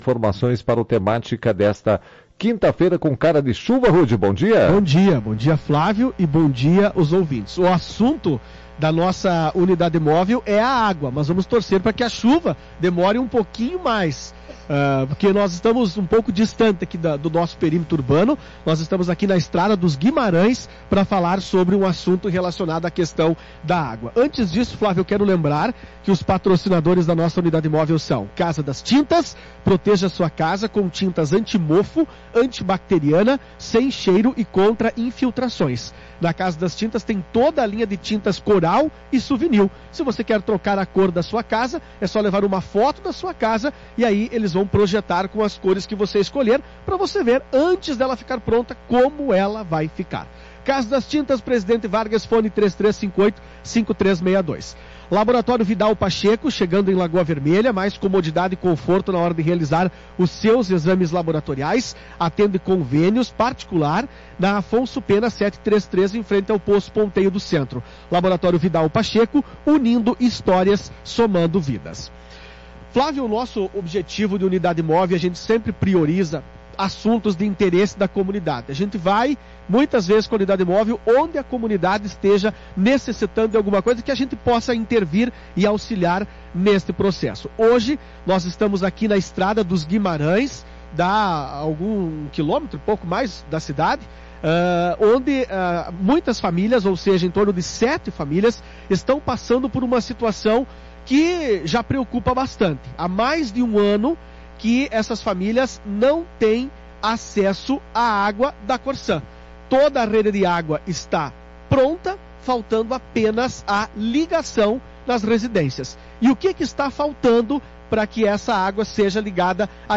Na manhã desta quinta-feira, dia 12 de setembro, a Unidade Móvel da Tua Rádio Cacique esteve na Estrada dos Guimarães.